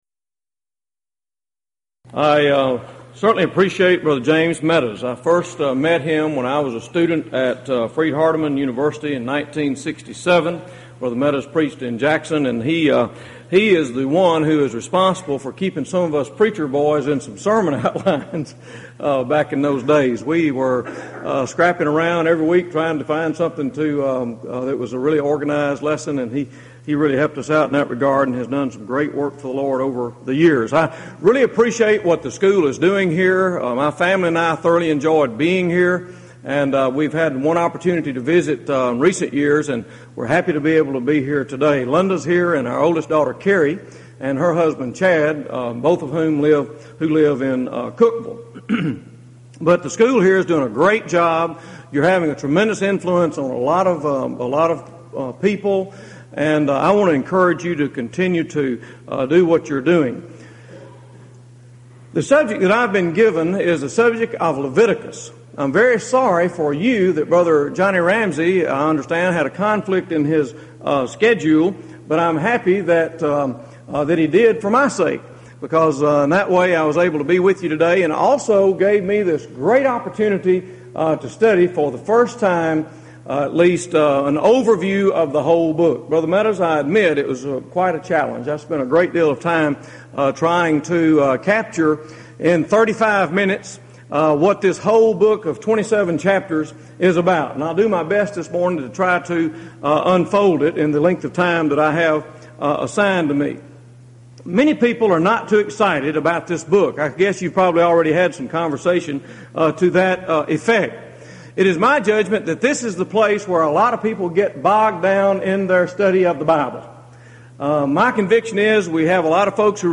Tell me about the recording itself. Event: 1998 East Tennessee School of Preaching Lectures